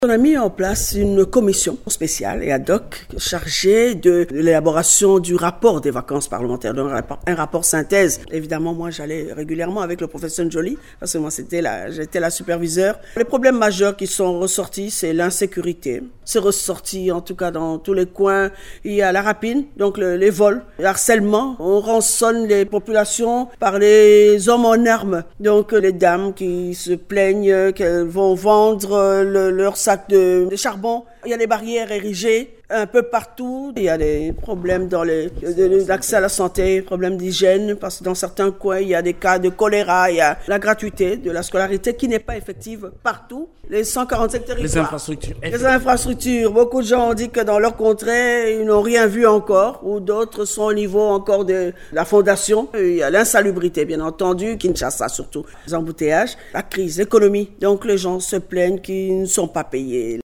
Dans une interview accordée mercredi 18 décembre à Radio Okapi, Dominique Munongo s’est félicitée de l’adoption par la plénière du rapport des vacances parlementaires de juin à septembre 2024 dernier.